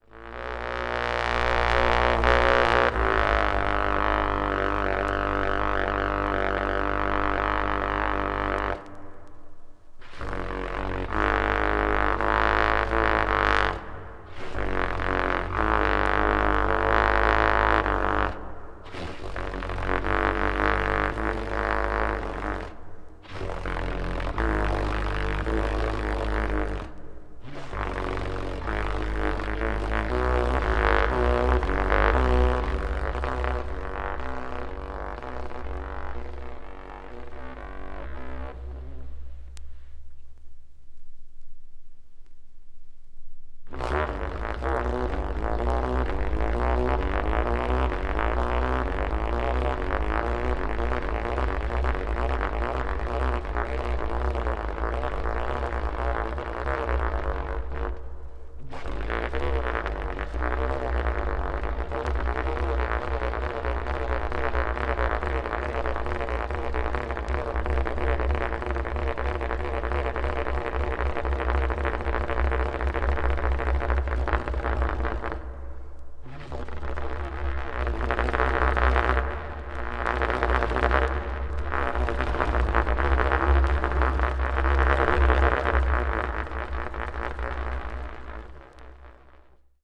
avant-jazz   contemporary jazz   free jazz   spiritual jazz